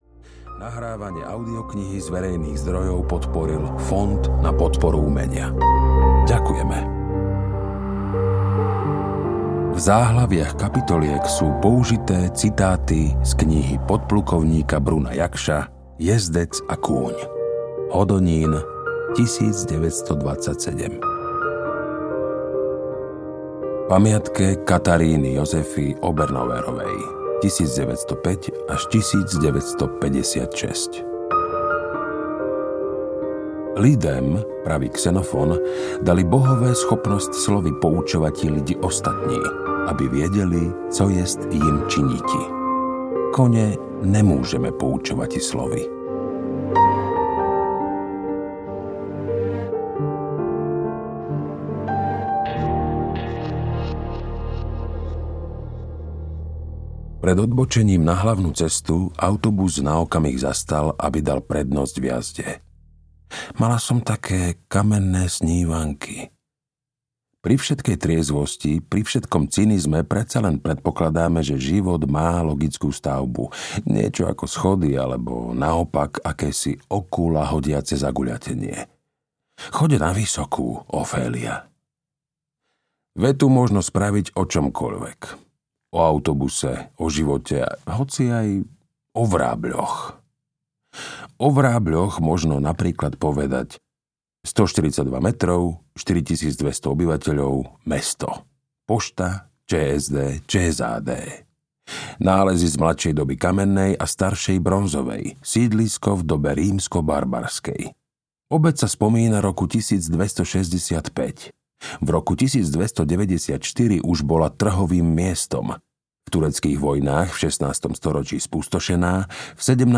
Kôň na poschodí, slepec vo Vrábľoch audiokniha
Ukázka z knihy
• InterpretKamil Mikulčík